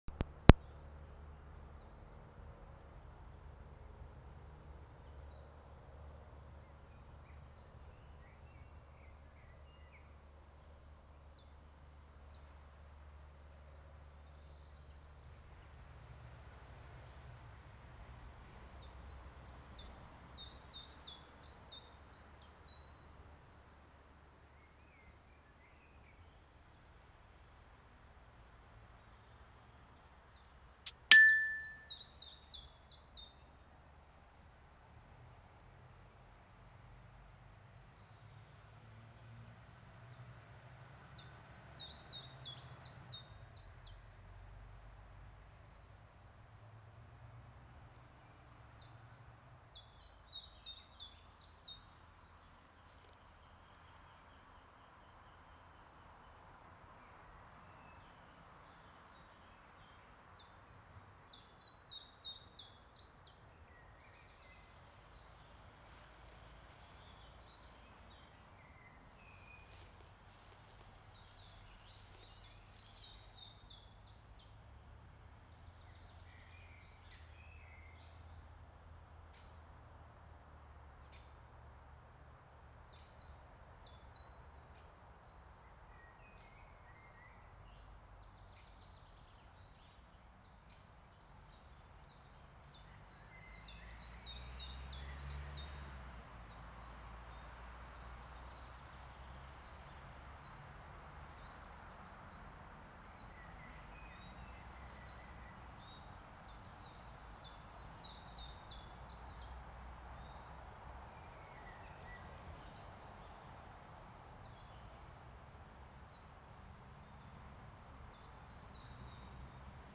Wryneck, Jynx torquilla
Administratīvā teritorijaRīga
StatusVoice, calls heard